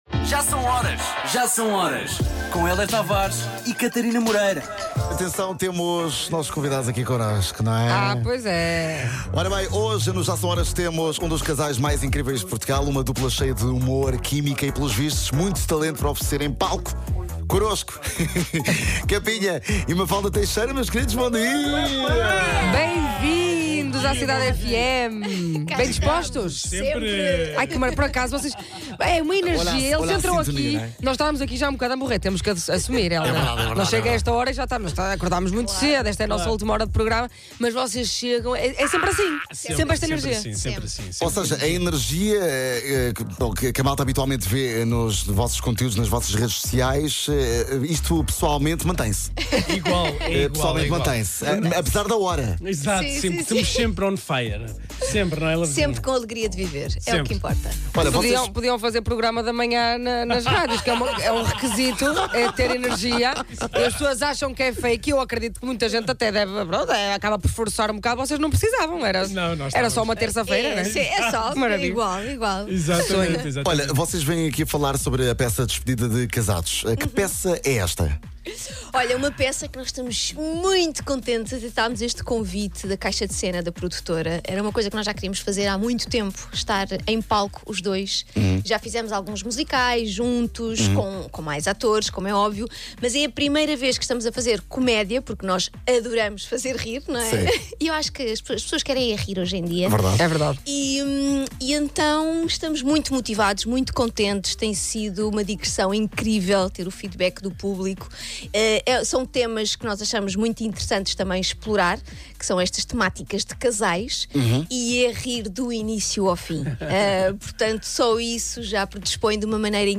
O Kapinha e a Mafalda Teixeira vieram até ao estúdio da Cidade FM e falaram sobre a DESPEDIDA DE CASADOS, a peça que junta o casal em cena.